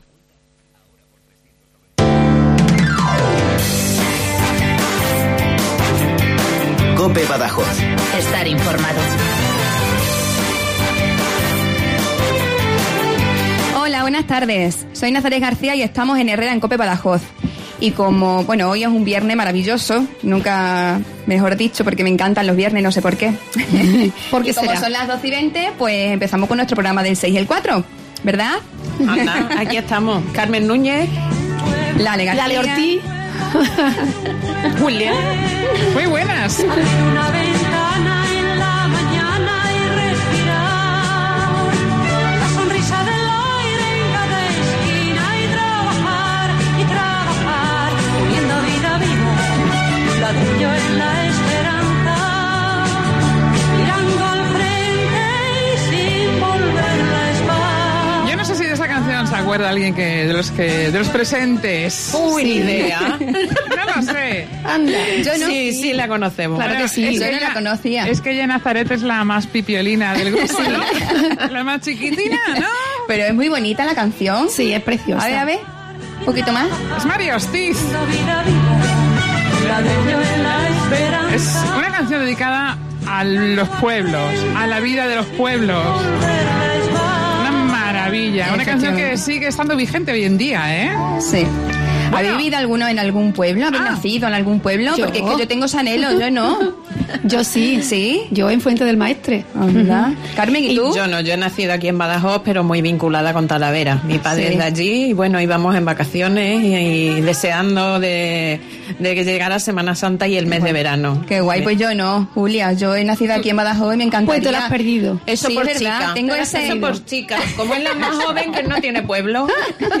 Nuestro invitado de hoy es Eduardo Jiménez, alcalde de Entrín Bajo